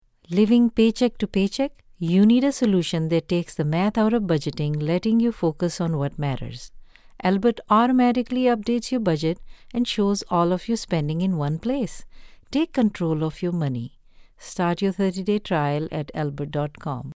Bank App AD - No music
The space is fully soundproofed to deliver clean, noise-free recordings.